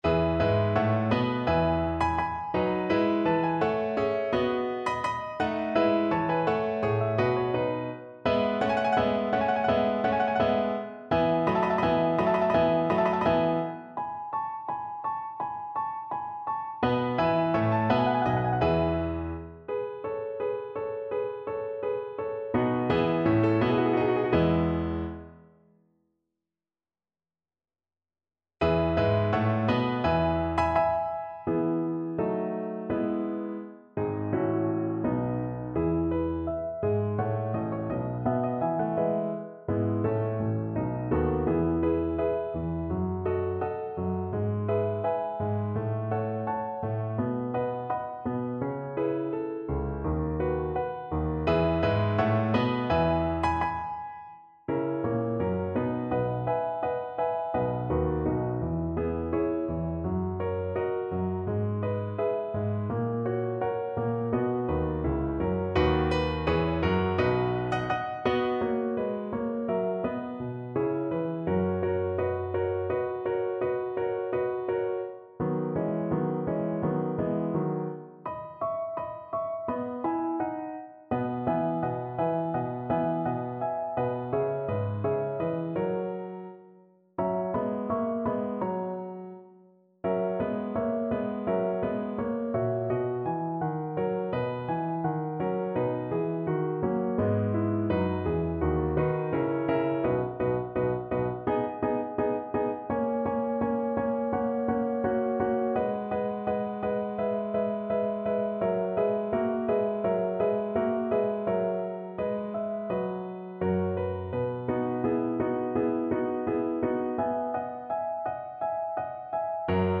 French Horn
4/4 (View more 4/4 Music)
Andante (=c.84)
E major (Sounding Pitch) B major (French Horn in F) (View more E major Music for French Horn )
Classical (View more Classical French Horn Music)